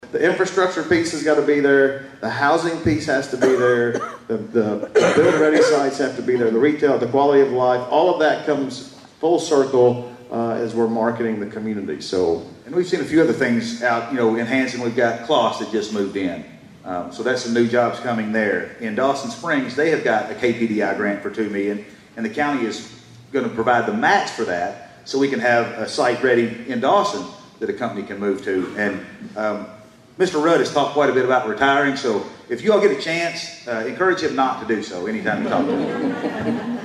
At the State of the Cities and County Address last week